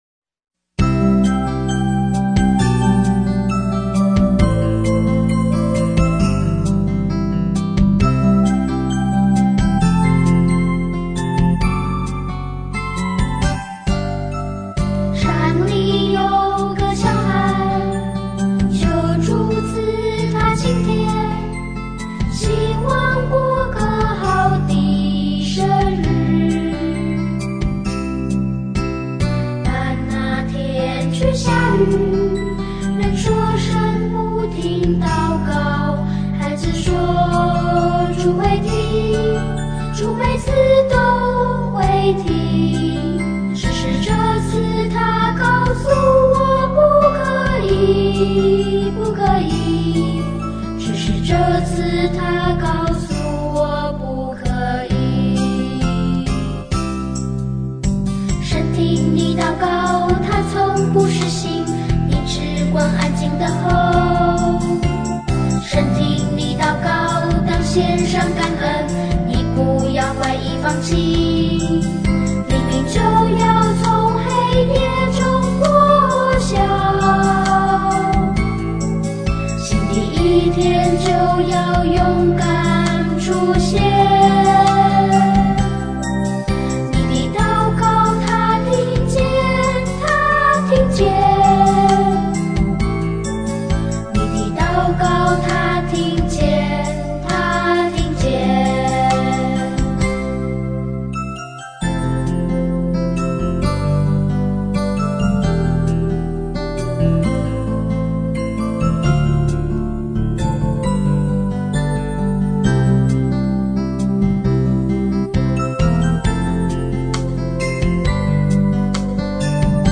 小孩子的祷告, 祷告, 小孩子